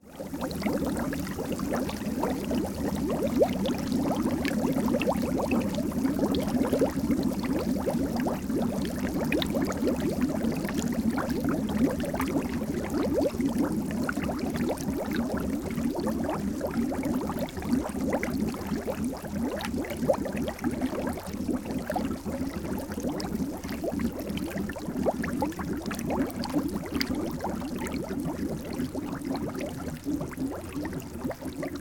bubbling.mp3